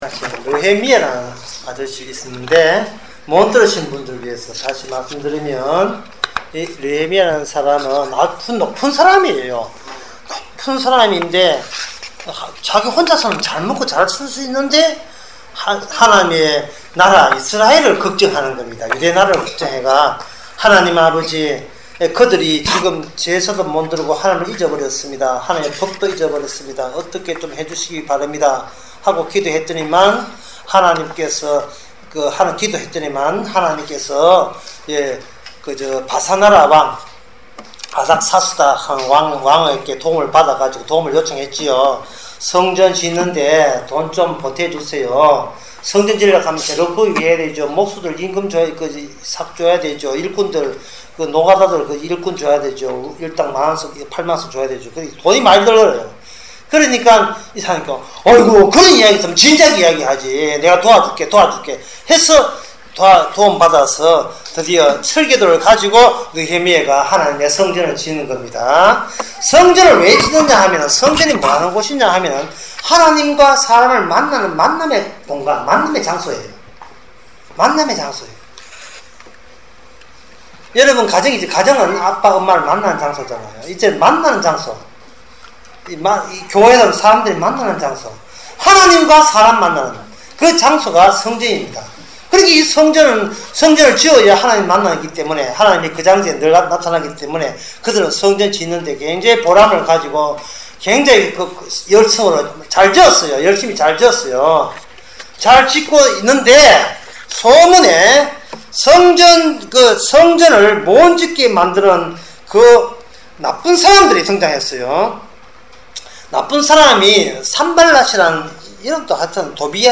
음성